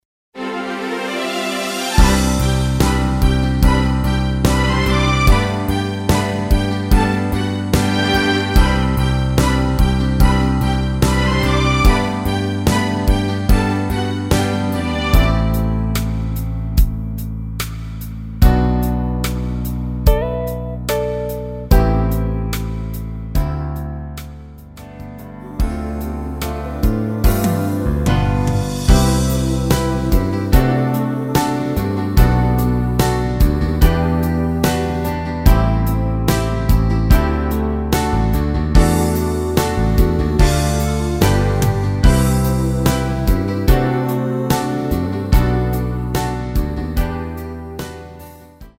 페이드 아웃곡이라 엔딩 만들어 놓았습니다.
원곡의 보컬 목소리를 MR에 약하게 넣어서 제작한 MR이며